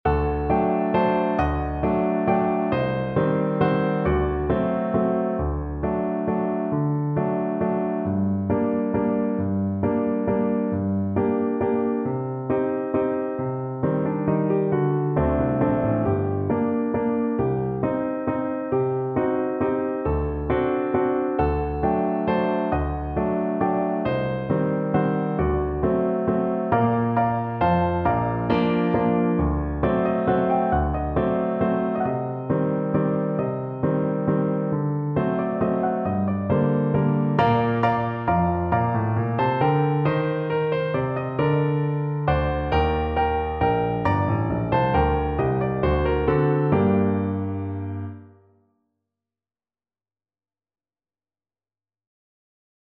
One in a bar .=c.45
3/4 (View more 3/4 Music)
world (View more world Voice Music)
Israeli